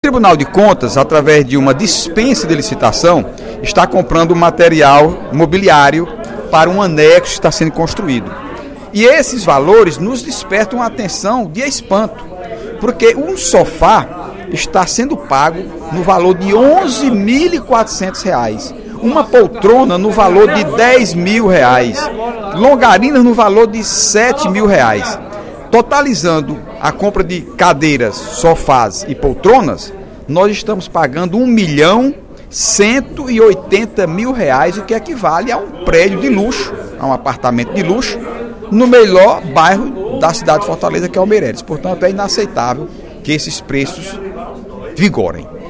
No primeiro expediente da sessão plenária desta quarta-feira (11/12), o deputado Heitor Férrer (PDT) criticou o contrato firmado entre o Tribunal de Contas do Estado (TCE) e a empresa Kentish Internacional Traders LTD no valor de R$ 1,180 milhão, para mobiliar um anexo do TCE que está sendo construído.